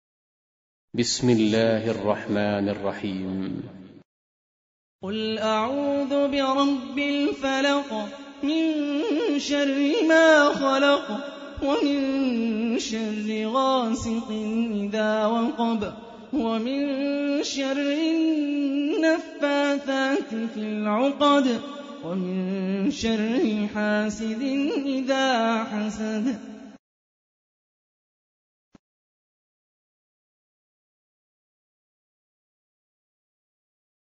113. Surah Al-Falaq سورة الفلق Audio Quran Tarteel Recitation